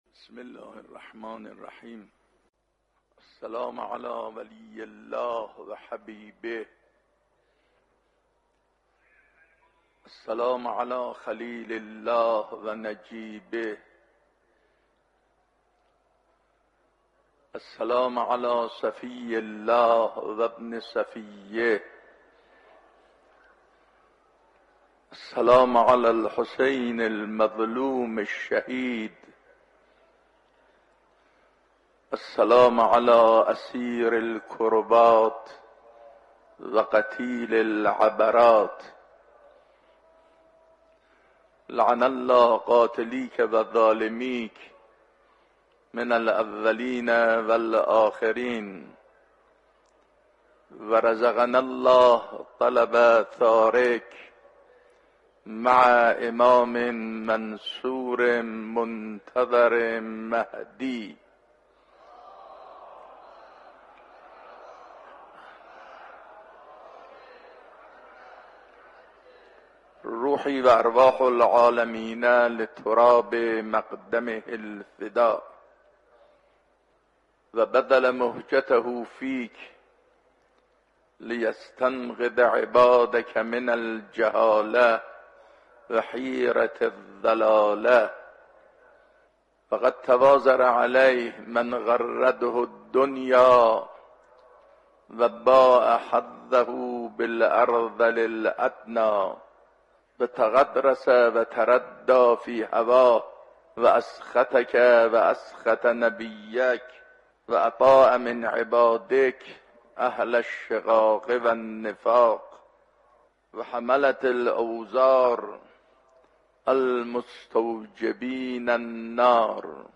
سخنرانی آیت الله سید احمد علم الهدی درباره زیارت اربعین و تکلیف شیعیان